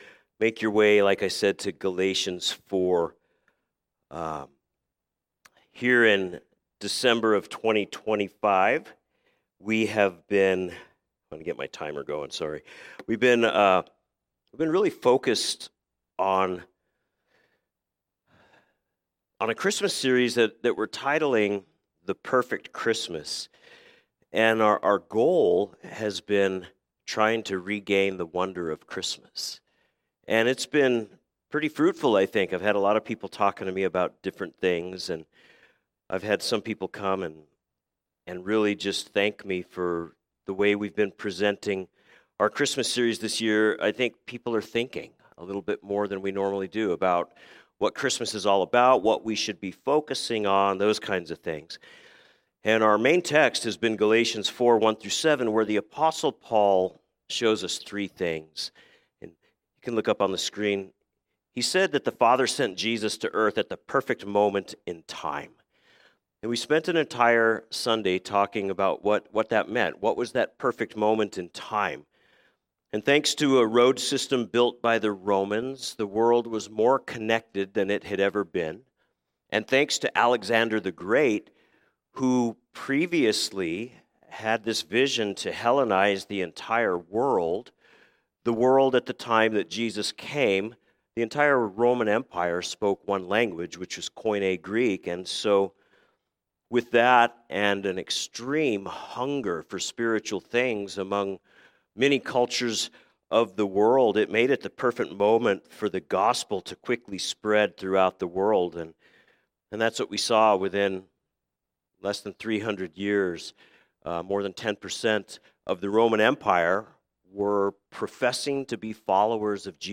A message from the series "Special Messages."